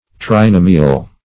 Trinomial \Tri*no"mi*al\, a.